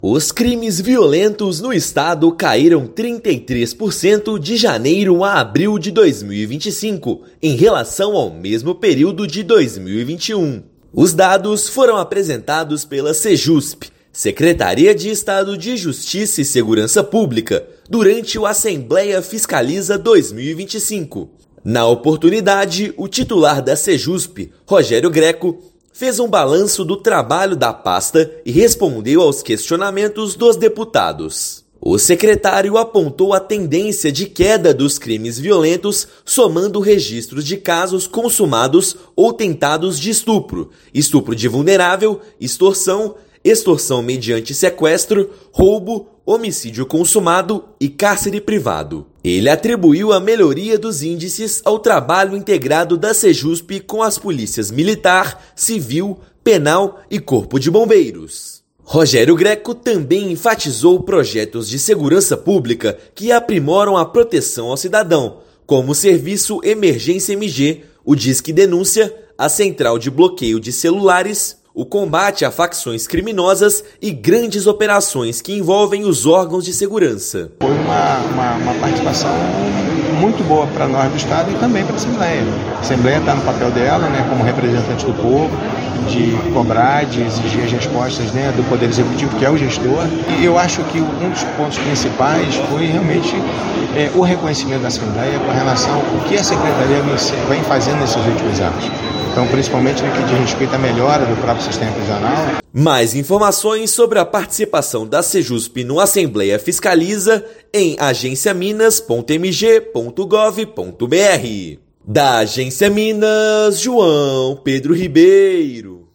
Prestação de conta da Sejusp ao Legislativo ainda destacou projetos de prevenção, ressocialização e estrutura para combate à criminalidade. Ouça matéria de rádio.